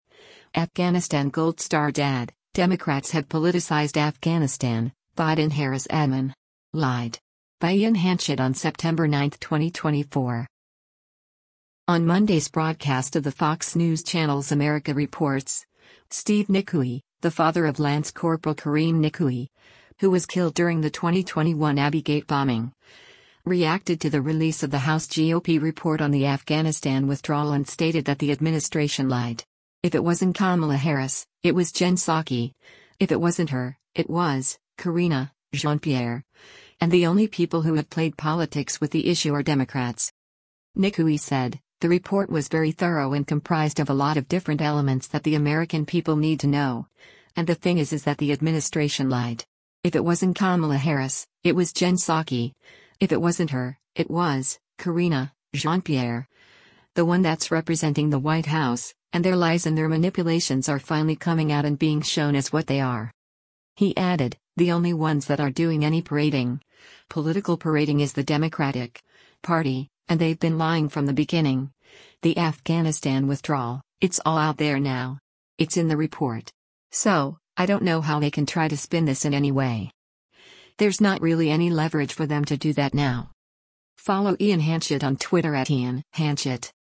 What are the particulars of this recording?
On Monday’s broadcast of the Fox News Channel’s “America Reports,”